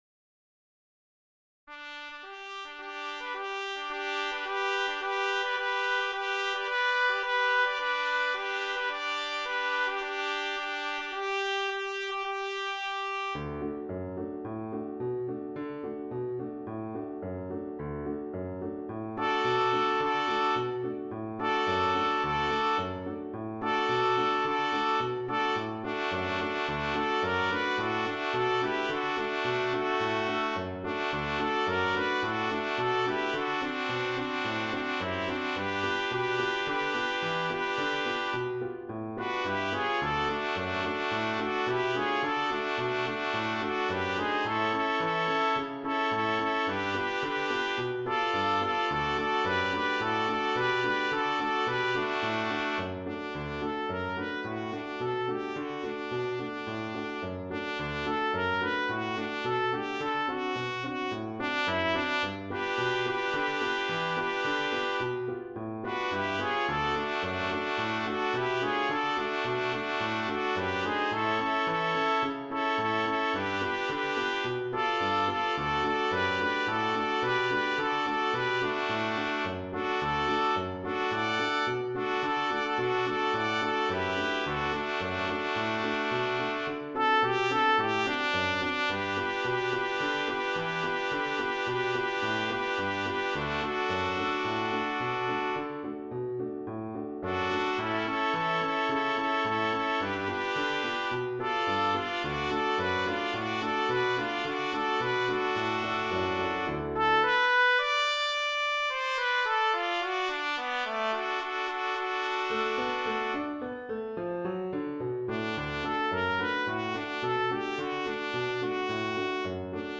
The recording is trumpet trio and piano. Tempo: mm 108